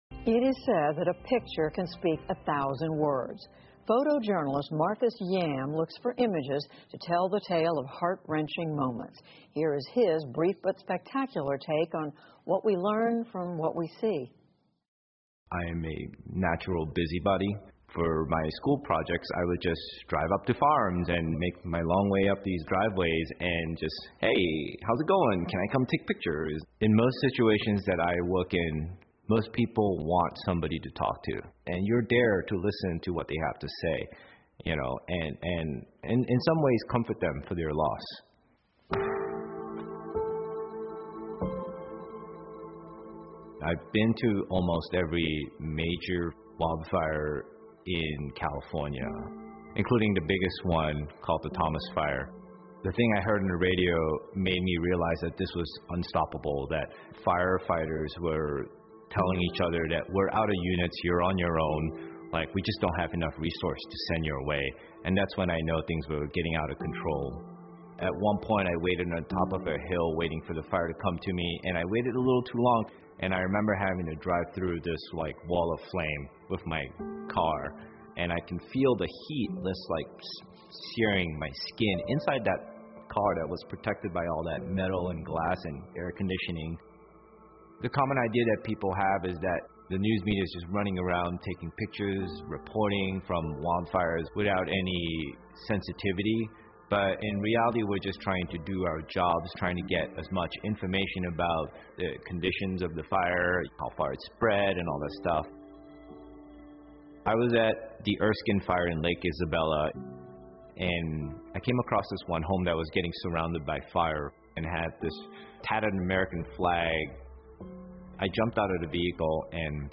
PBS高端访谈:用照片讲述无情大火 听力文件下载—在线英语听力室